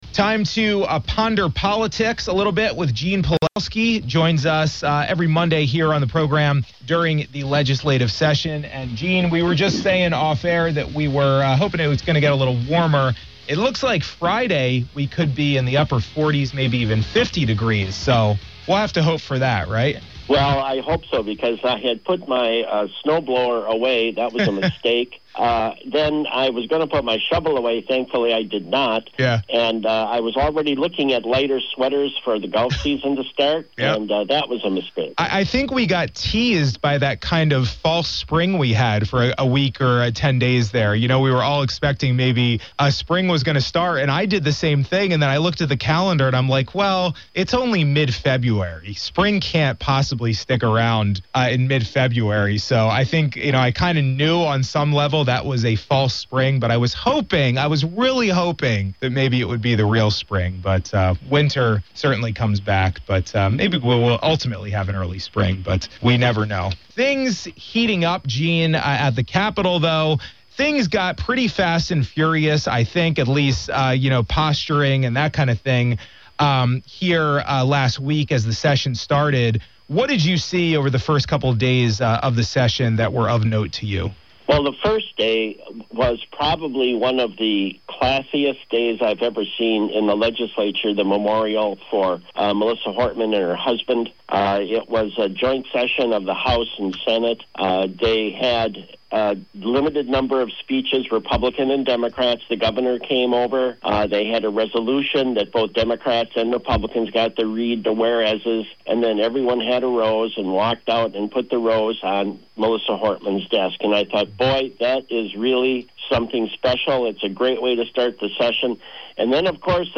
(KWNO)-Listen to our most recent conversation with former State Representative Gene Pelowski.